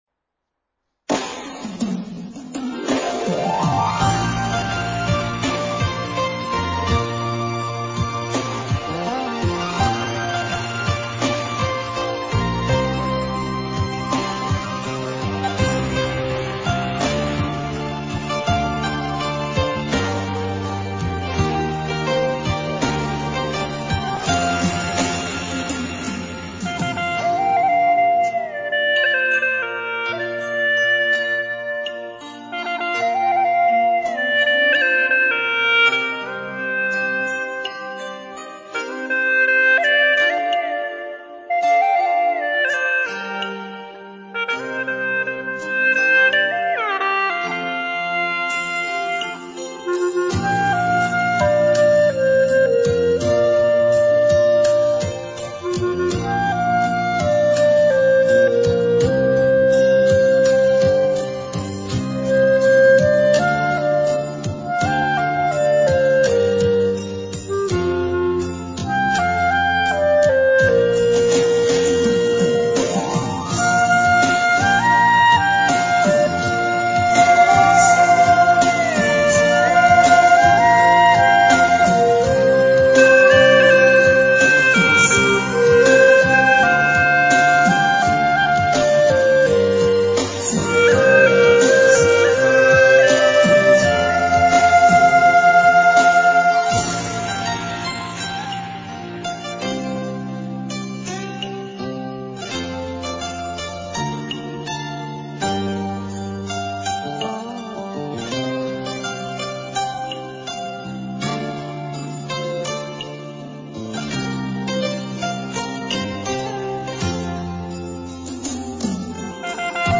调式 : 降B 曲类 : 流行